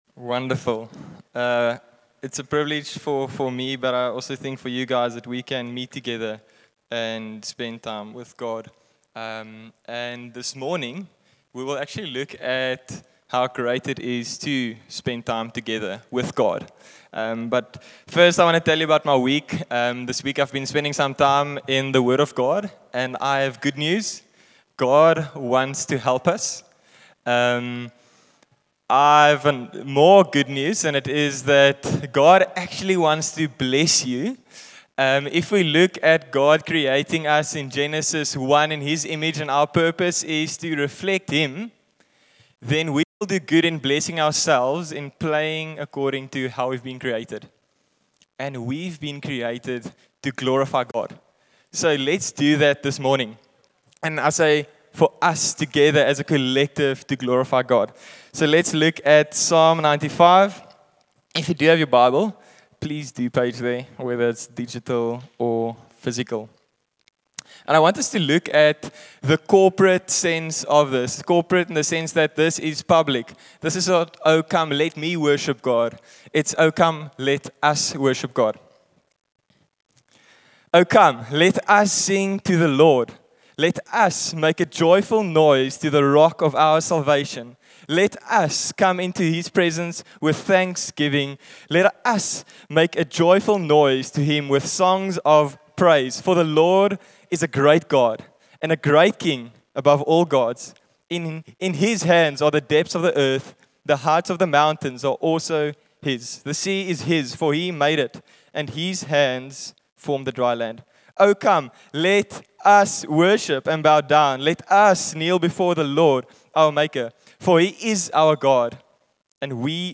Listen to Sunday meeting messages from across our congregations in South Africa.